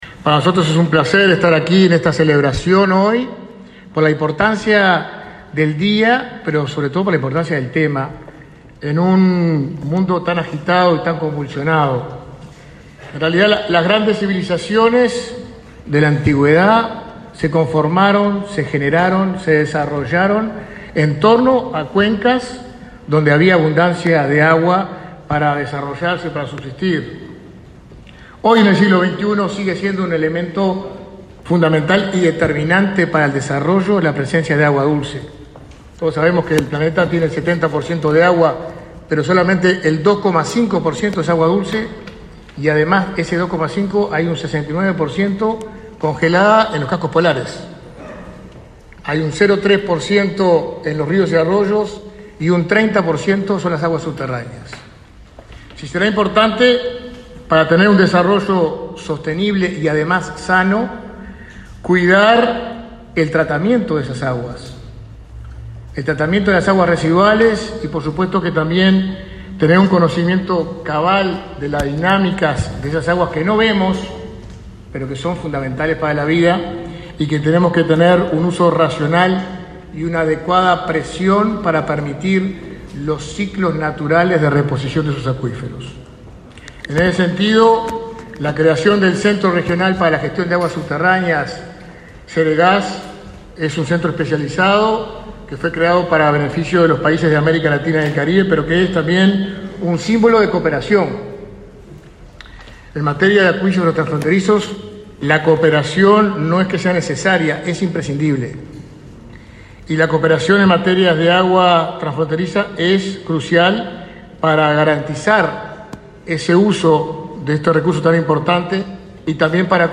Palabras del ministro interino de Ambiente, Gerardo Amarilla
El ministro interino de Ambiente, Gerardo Amarilla, participó este martes 22 en Montevideo de un acto conmemorativo del Día Mundial del Agua.